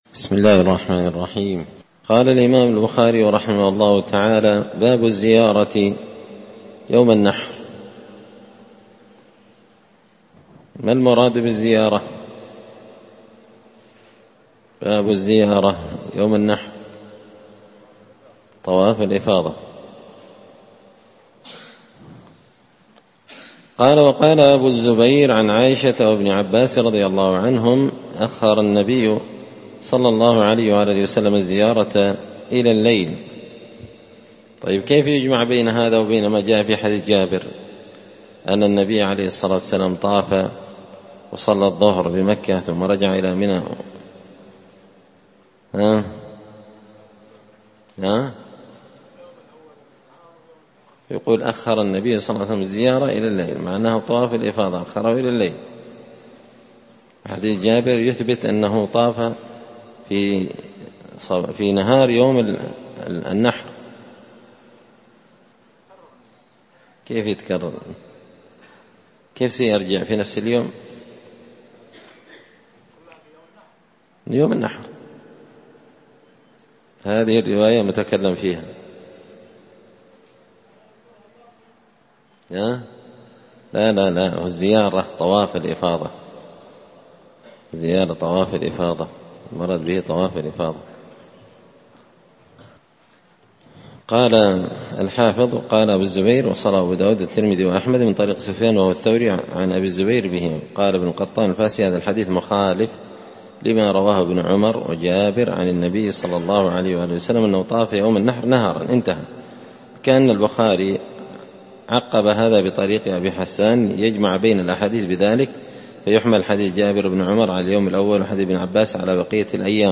كتاب الحج من شرح صحيح البخاري – الدرس 114